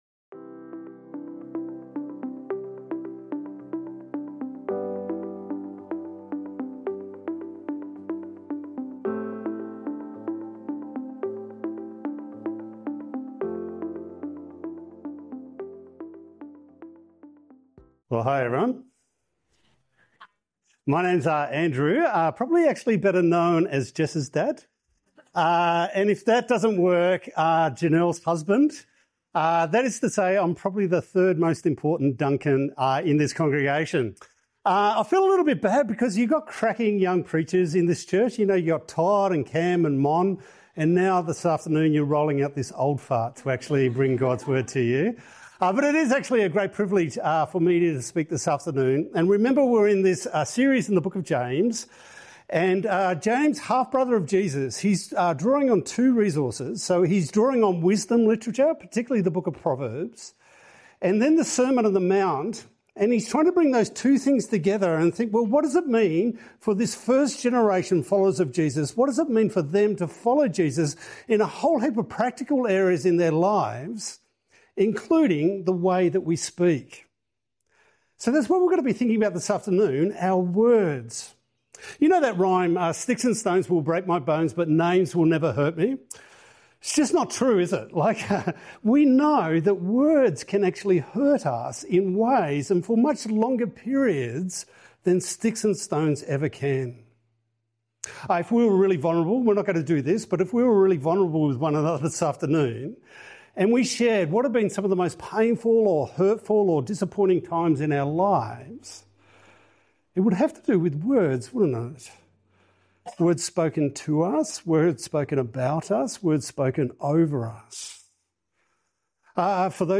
Words Have Power | Sermon on James 3 – H3O Church